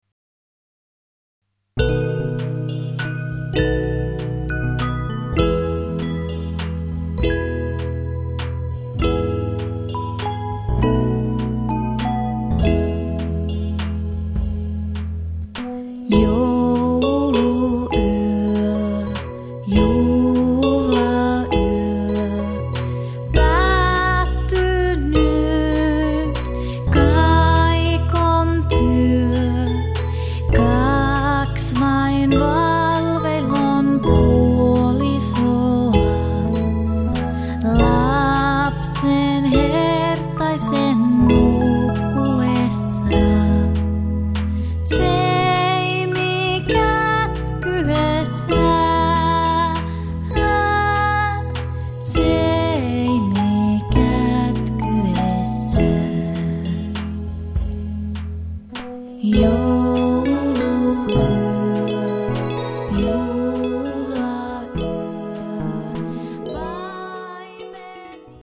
laulut
kitarat
Äänitys on tehty kotistudiossa Kemissä.
Muut instrumentit on ohjelmoitu MIDI-tekniikalla.